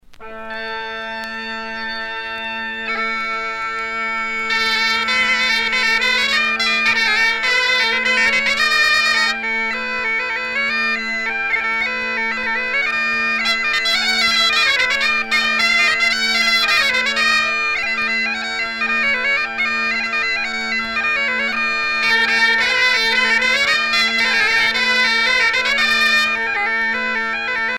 danse : passepied
Pièce musicale éditée